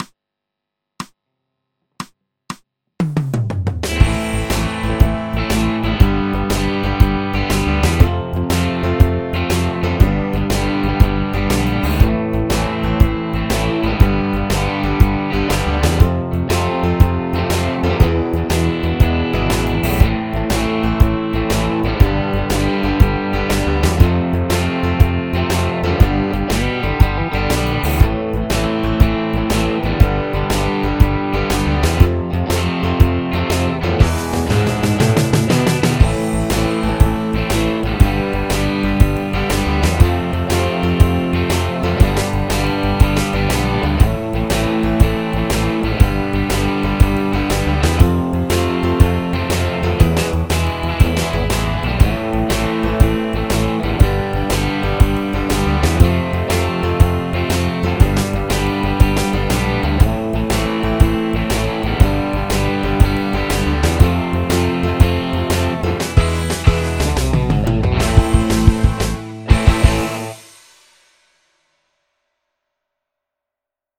マイナー・ペンタトニック・スケール ギタースケールハンドブック -島村楽器